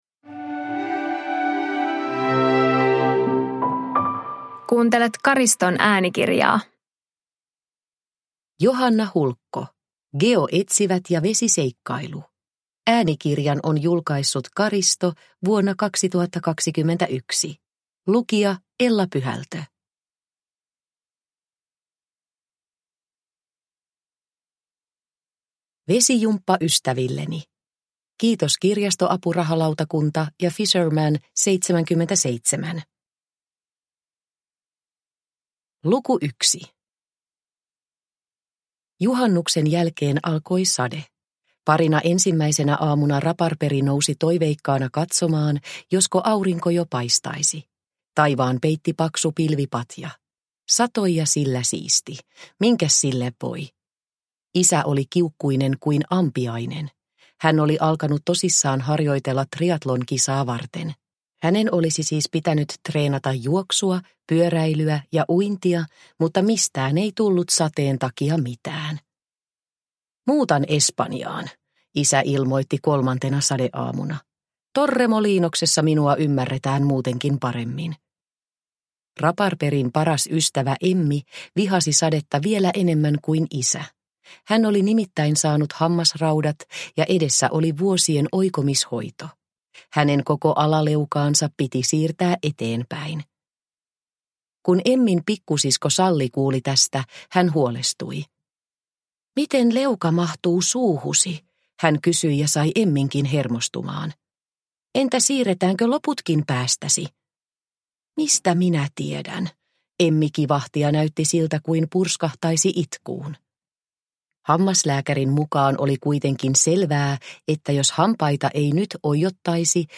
Geoetsivät ja vesiseikkailu – Ljudbok – Laddas ner